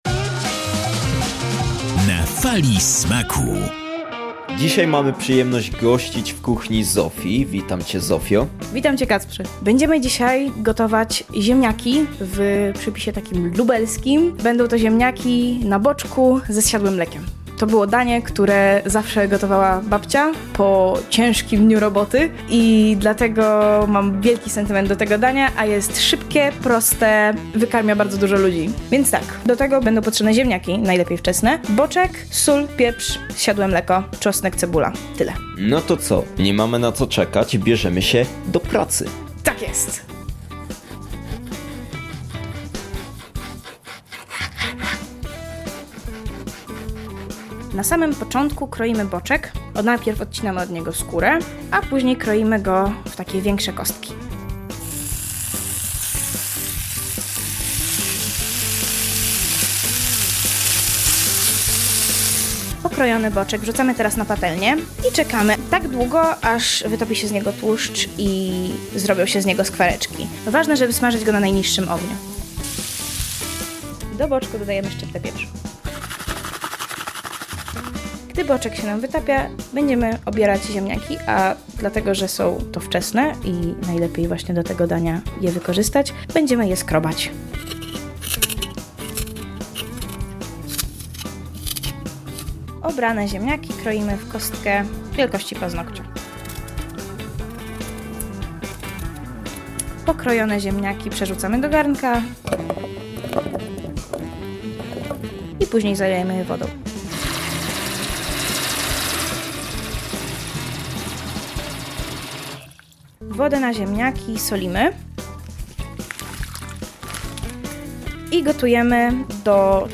Uwielbia rozmawiać na różne tematy, a dziś zaprasza nas do swojej kuchni, by podzielić się przepisem na jedno ze swoich ulubionych dań.